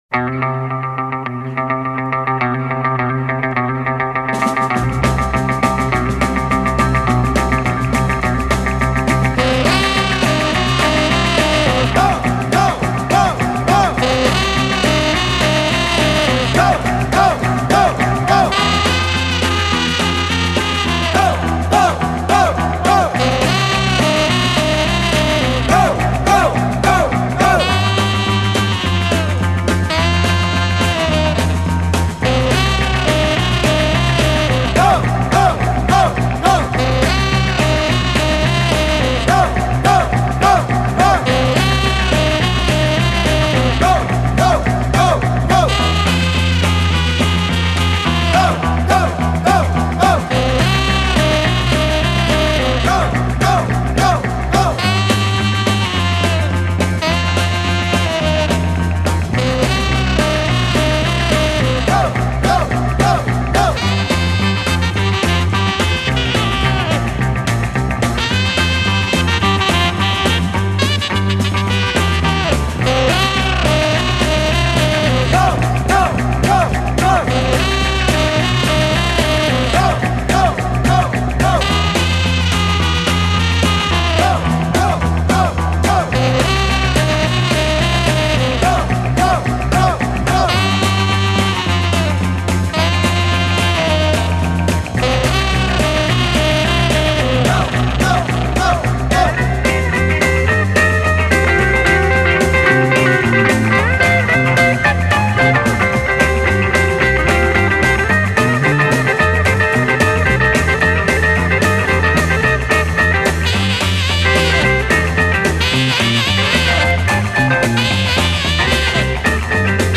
Sax
Gitarre